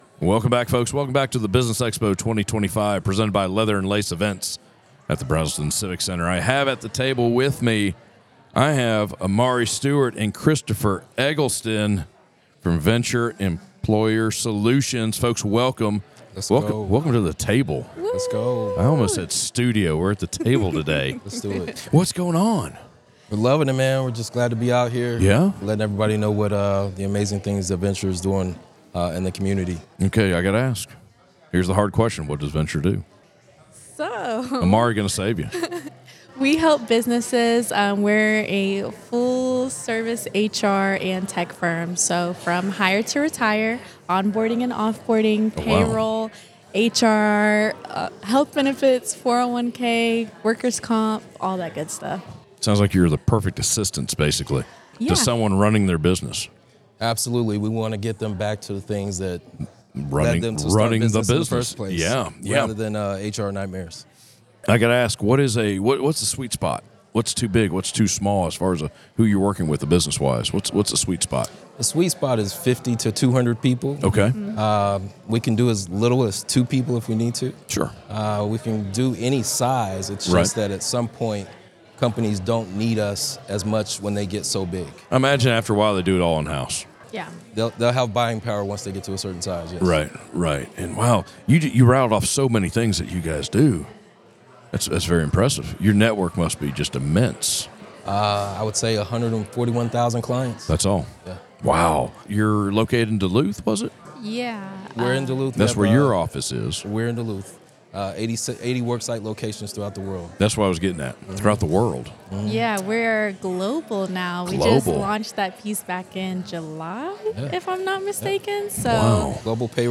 Business Expo 2025 presented by Leather & Lace Events at the Braselton Civic Center
Northeast Georgia Business RadioX – the official Podcast Studio of the Business Expo 2025